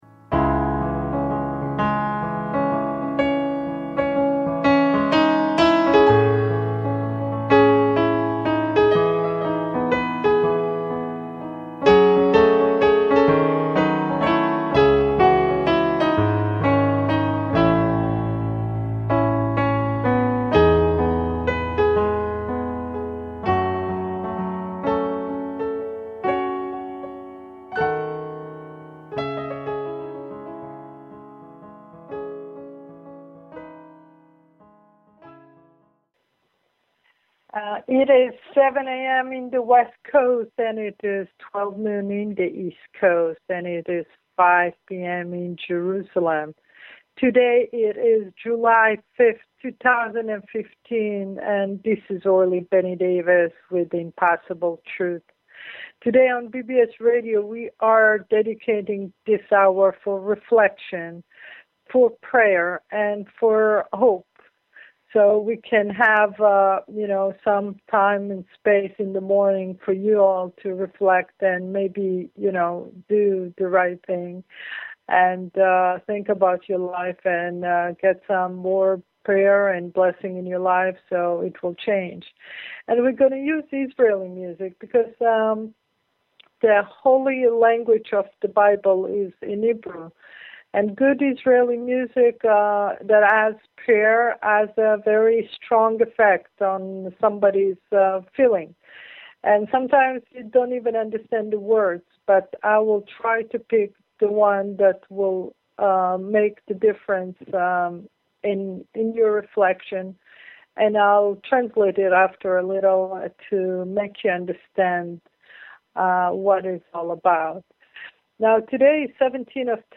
Talk Show Episode
and featuring Music from Israel